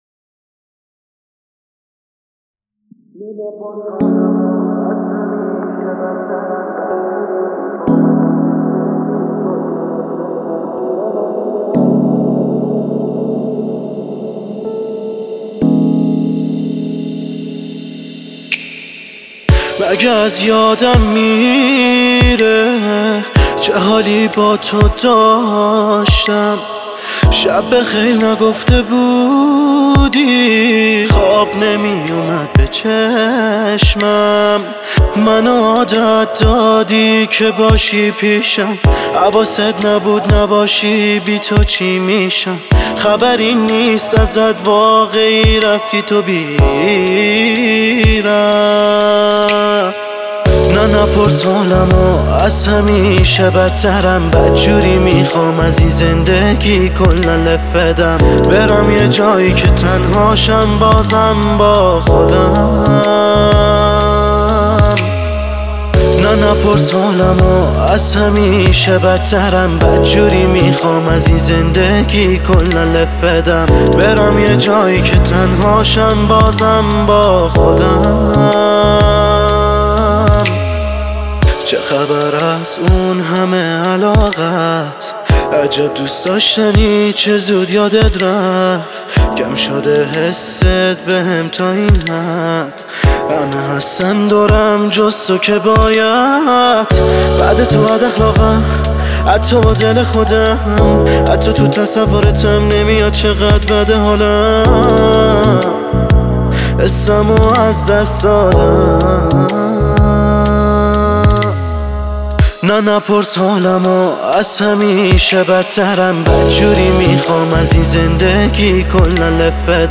این آهنگ پر از احساسات دلتنگی و عاشقانه است.